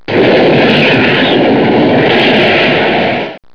mysteryroar1.wav